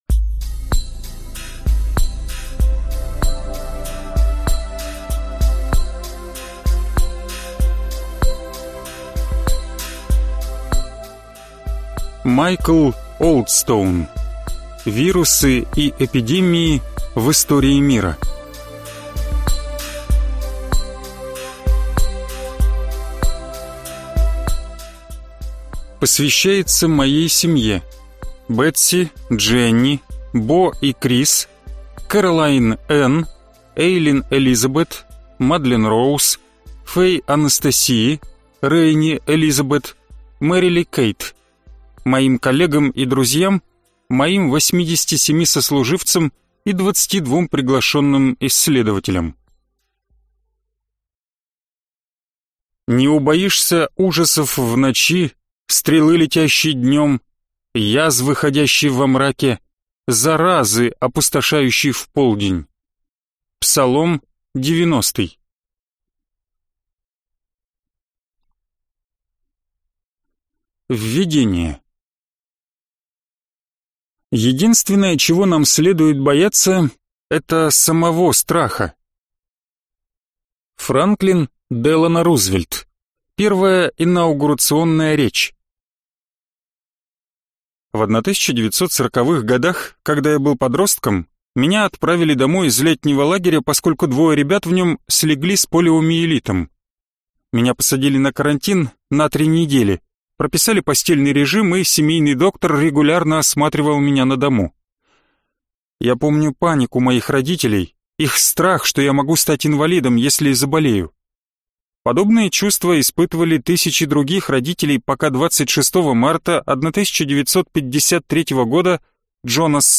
Аудиокнига Вирусы и эпидемии в истории мира. Прошлое, настоящее и будущее | Библиотека аудиокниг